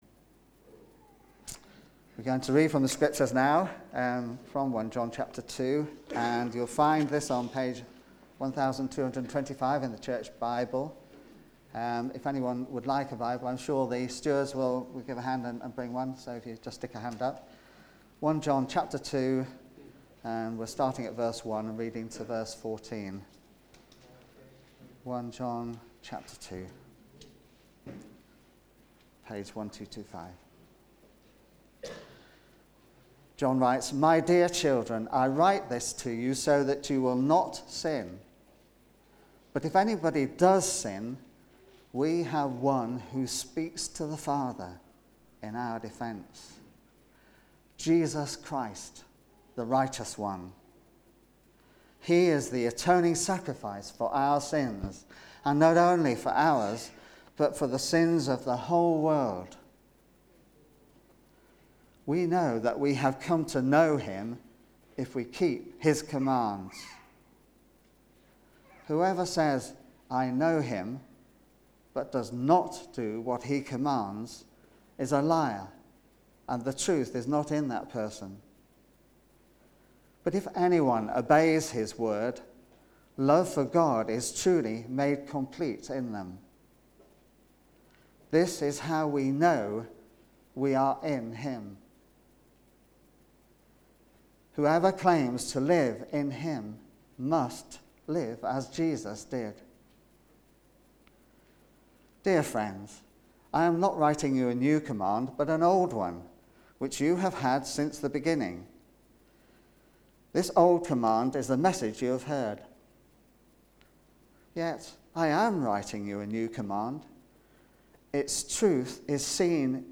Living in the Light Sermon